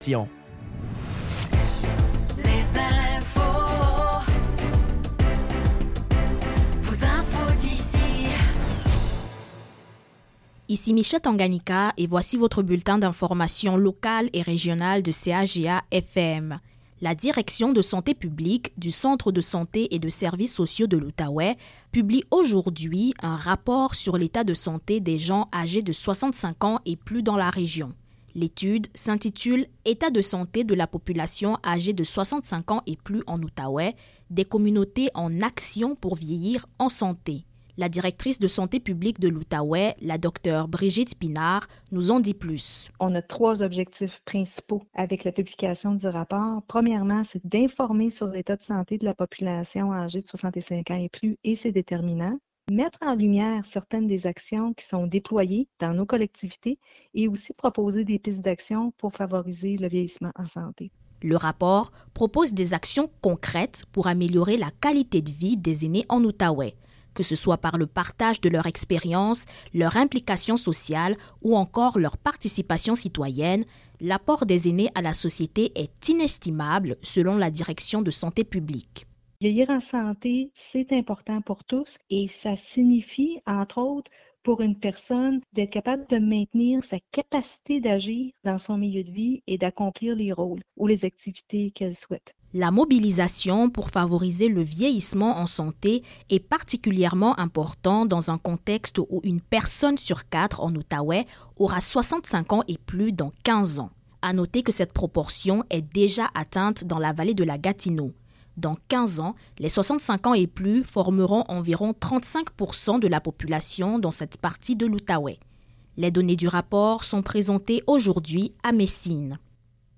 Bulletins de nouvelles | Page 9 sur 1040 | CHGA